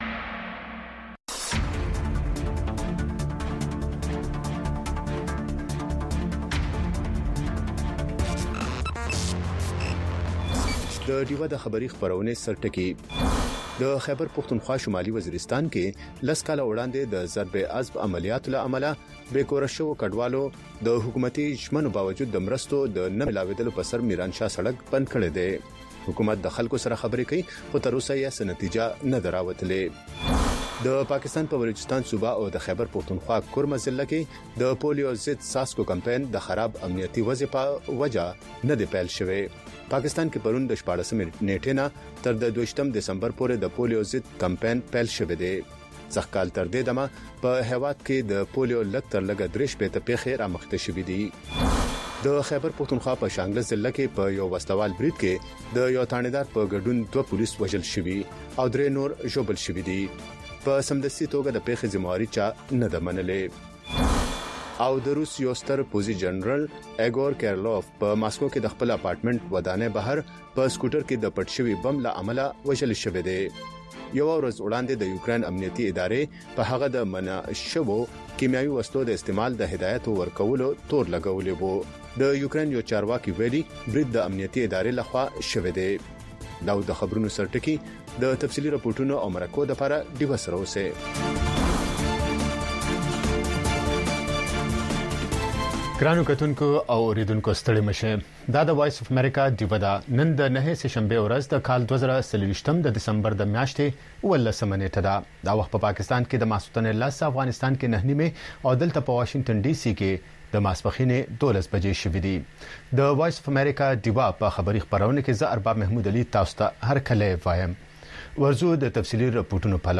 خبرونه
د وی او اې ډيوه راډيو ماښامنۍ خبرونه چالان کړئ اؤ د ورځې د مهمو تازه خبرونو سرليکونه واورئ.